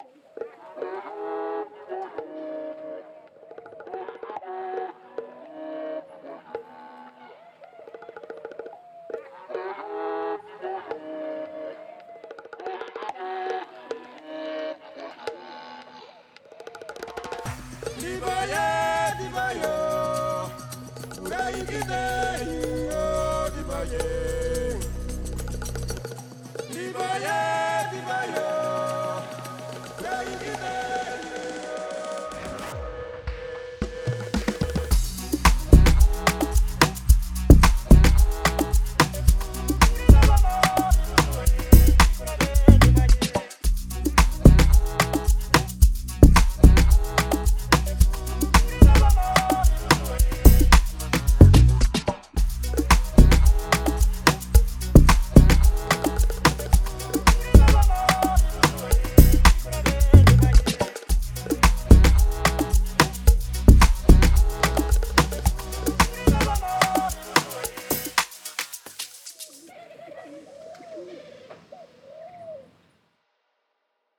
צפו: יצירת טראק אפרו-האוס בלייב (עם שבט אפריקאי) 🇵🇬